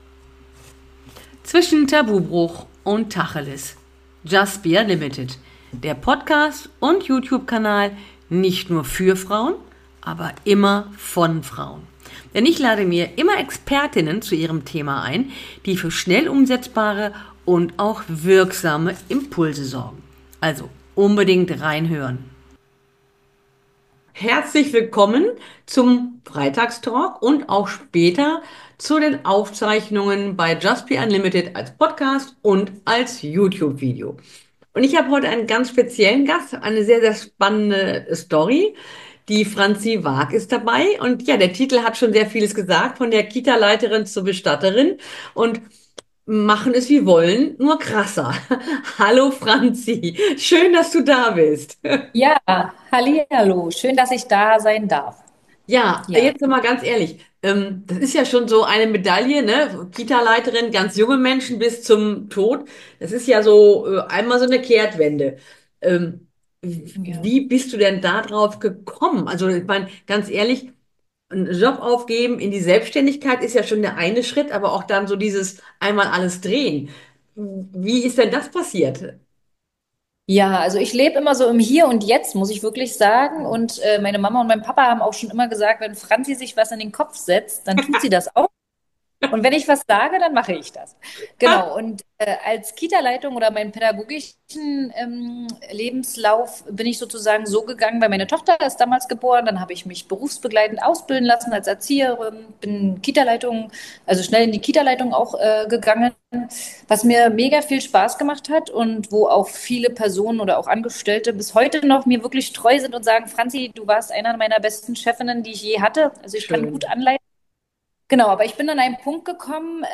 Warum hält uns die Angst vor der Unsicherheit oder dem Risiko so oft davon ab? Es wird ein spannender und sicher auch sehr emotionaler Talk.